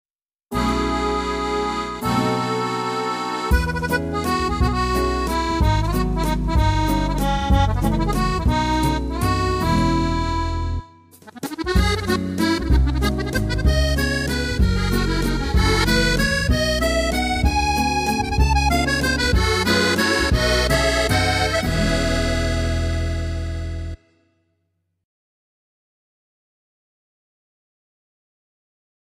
Accordion
Sound - Accordion.mp3